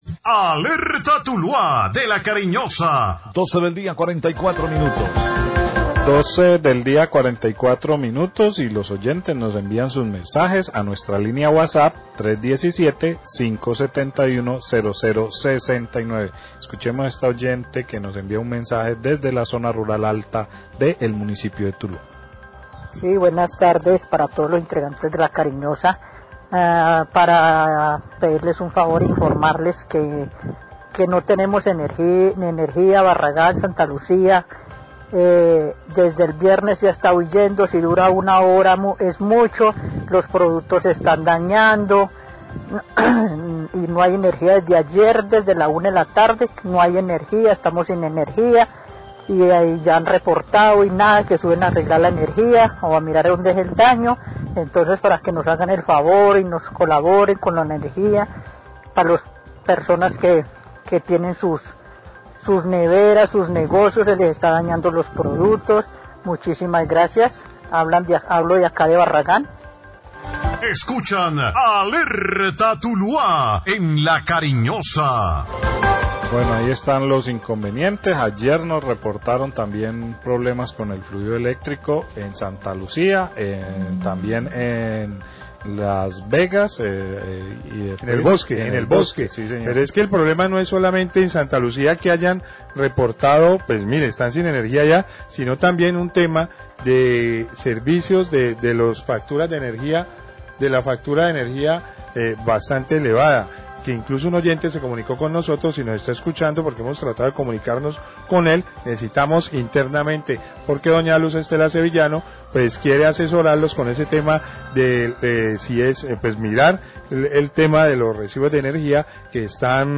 Radio
A través de audios de whatsapp, dos usuarios del cgto de Barragán se quejan por los cortes de energía que ya llevan dos días en esa región.